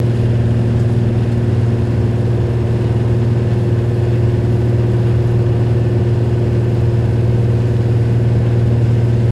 Cessna Interior Loop High Speed, Mono